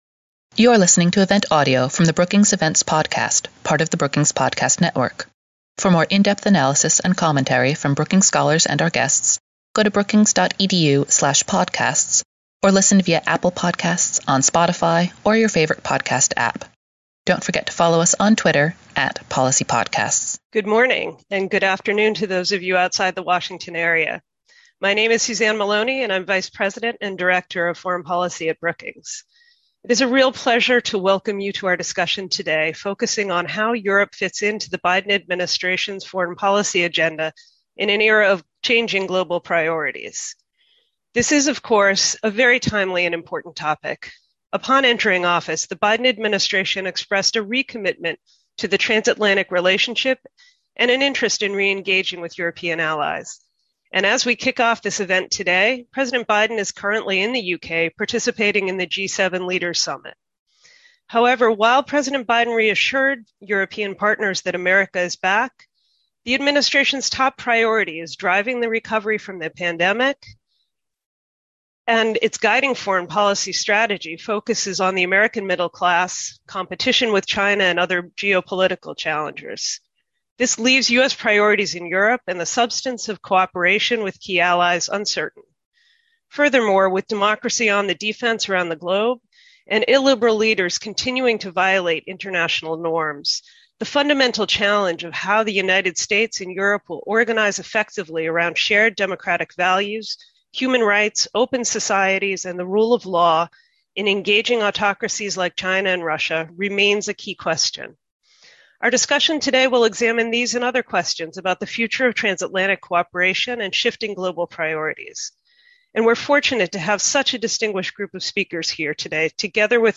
On June 11, Center on the United States and Europe at Brookings will host a conference to examine trans-Atlantic relations in the Biden era.